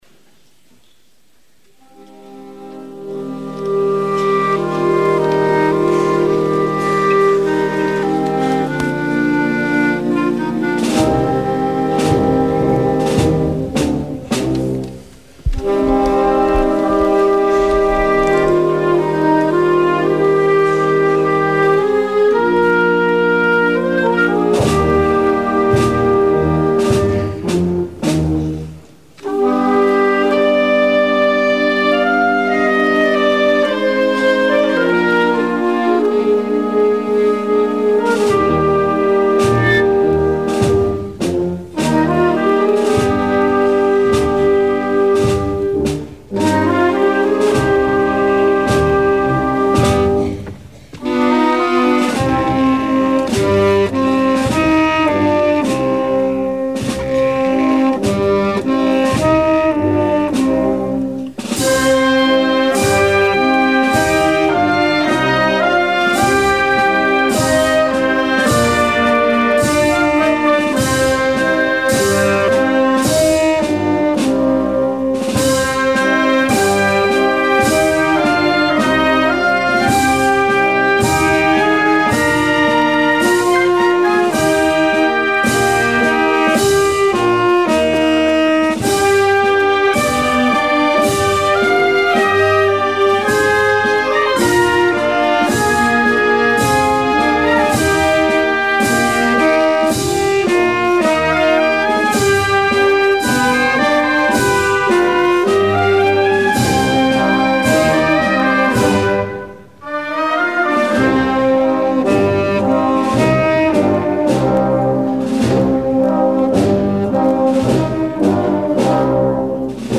Pregón de la Semana Santa de Jerez 2009
Sonido: Canarl Sur Radio